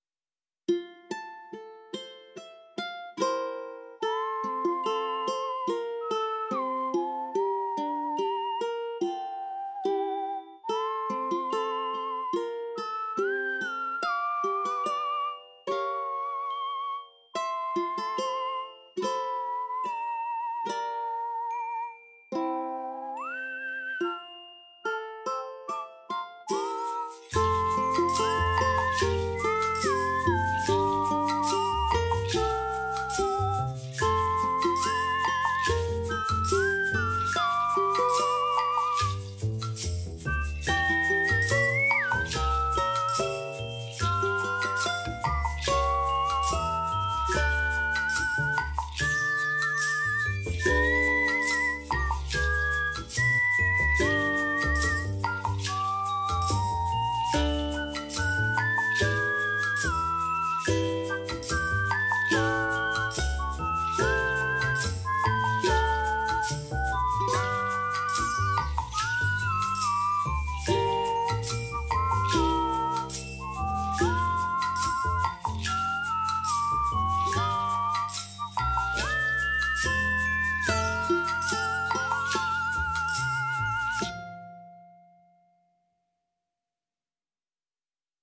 我们简单乐器系列的另一个成员：简单口哨
该库提供了一组独特的功能，非常适合任何想要创作具有轻盈、简单和宁静声音的音乐的人。
此外，Simple Whistle 提供三种不同的乐器：正常（MW Dyn）、颤音（MW Dyn）和颤音控制（MW 颤音）。
该库还包括五个发音，包括连奏、波塔门托、延音、断断续续和短断音，让您可以为您的音乐创造广泛的声音。